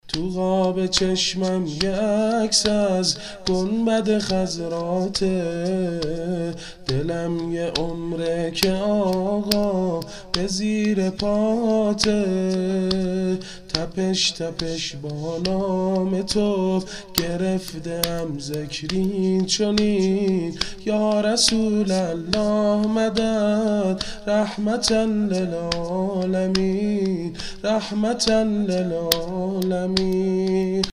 شور ، زمینه